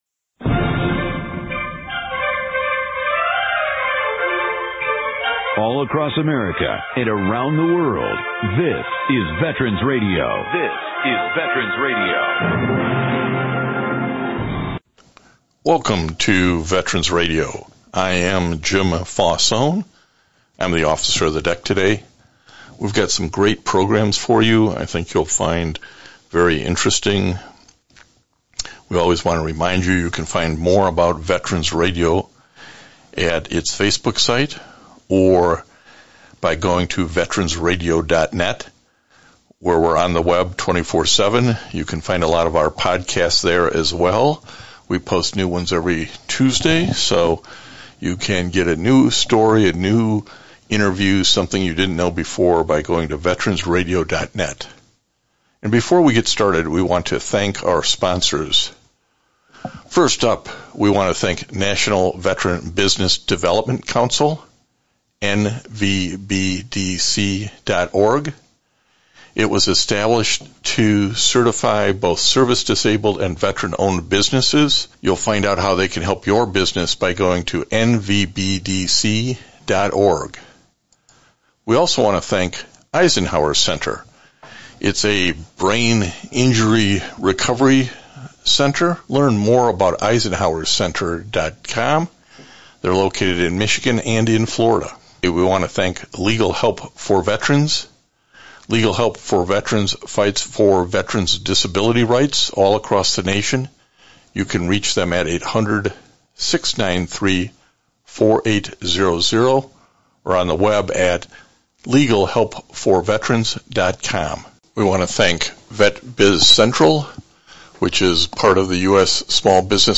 This interview focuses on the reasons she joined the Navy and what she got out of that service.